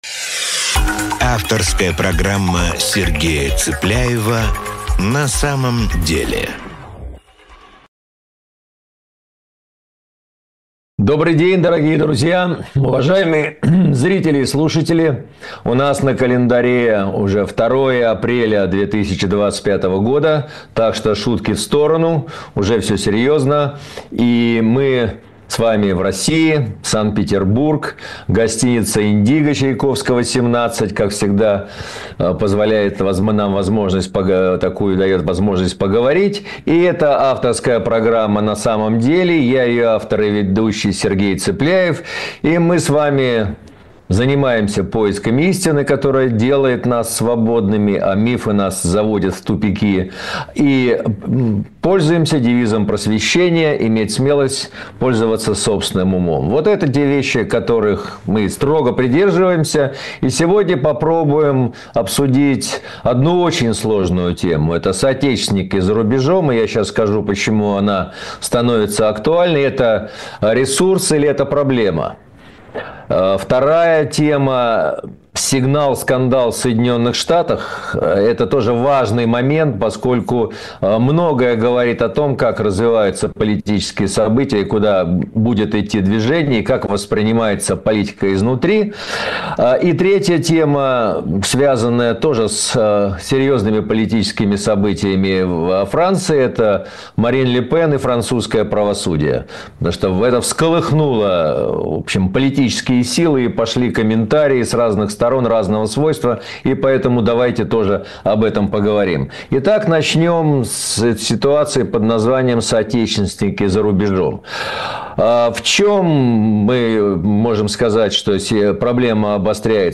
Эфир ведёт Сергей Цыпляев